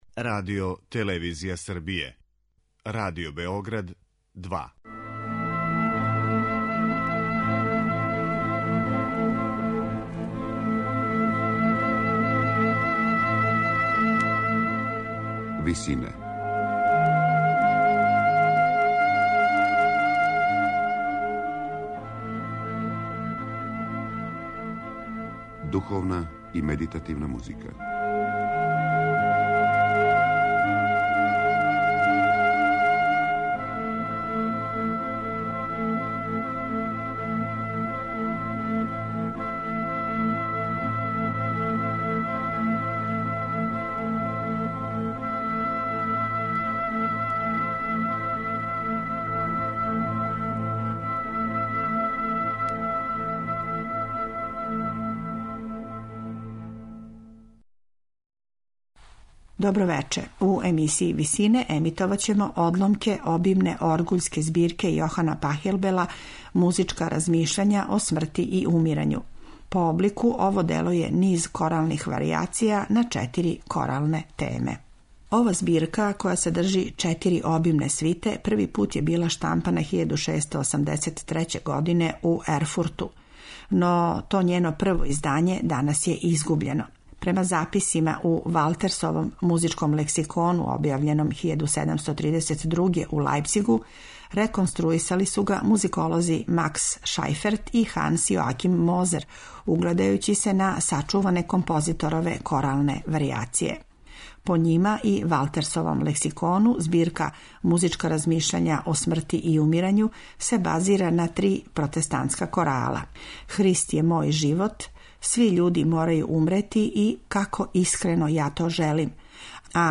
Емитоваћемо одломке обимне оргуљске збирке Јохана Пахелбела 'Музичка размишљања о смрти и умирању'.
По облику, ово дело је низ варијација на четири коралне теме.